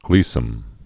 (glēsəm)